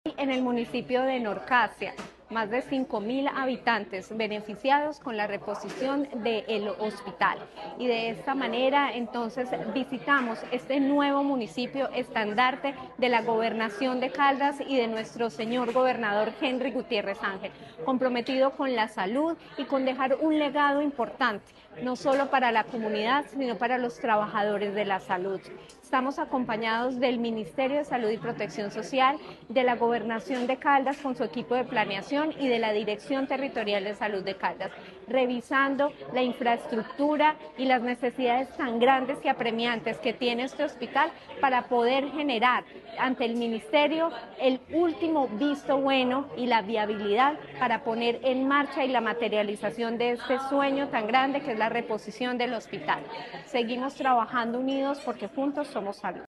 Natalia Castaño Díaz, directora de la DTSC.